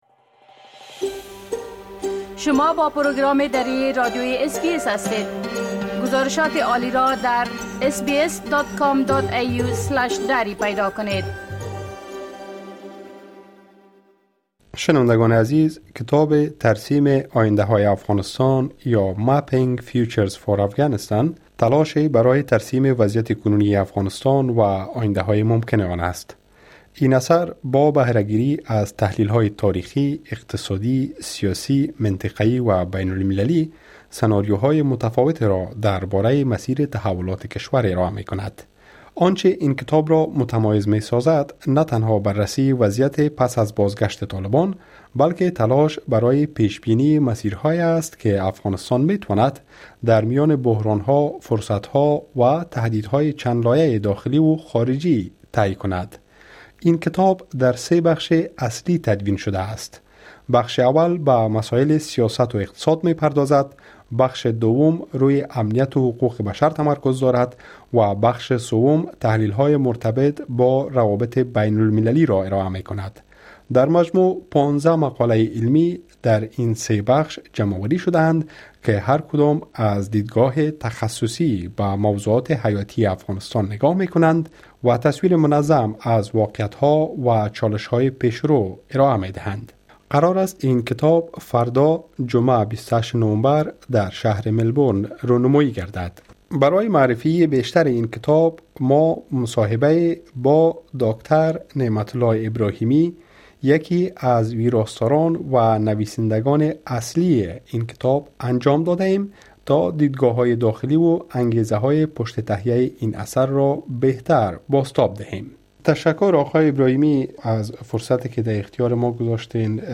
رونمایی از کتاب جدیدی‌که روایت آینده افغانستان را بازمی‌نویسد؛ گفتگو با یکی از نویسندگان